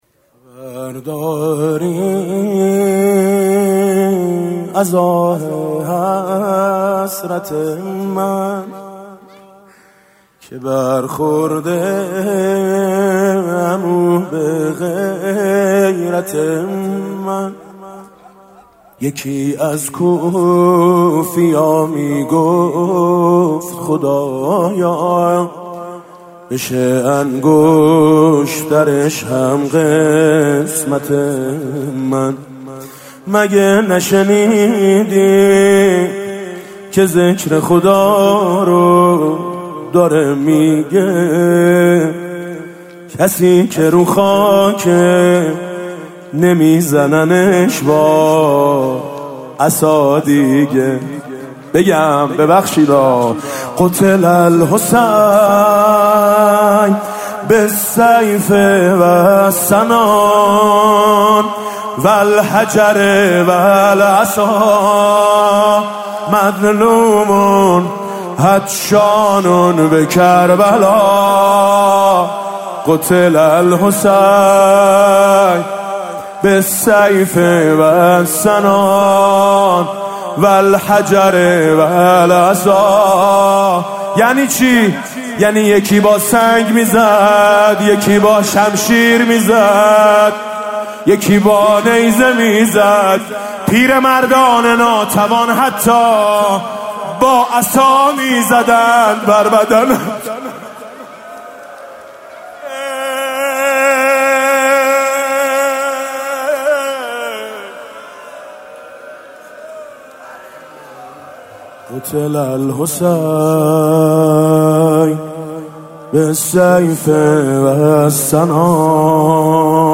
مداحی حاج میثم مطیعی دهه اول محرم 99
دانلود مراسم شب اول محرم ۹۹ به صورت یکجا